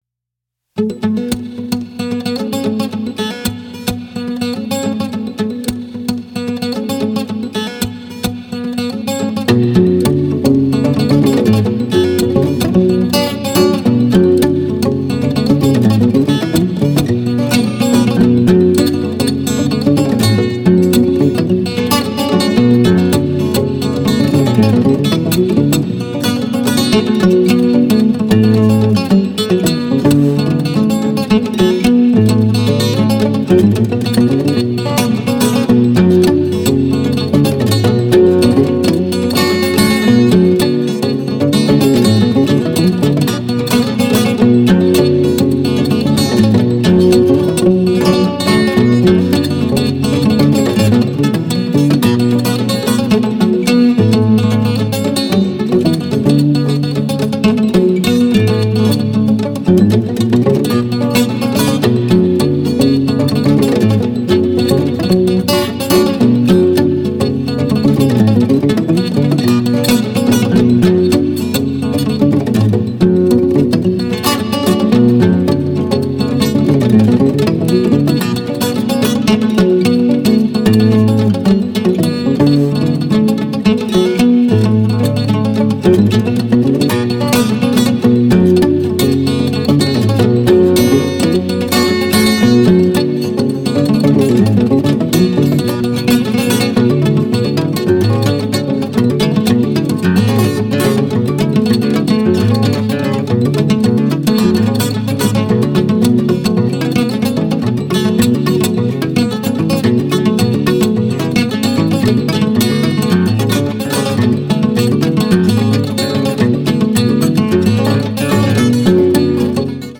かなりプログレッブな感覚が横溢した作品となっていますね！
ロックのエッセンスがかなり出ていて
濃厚な演奏を楽しめます！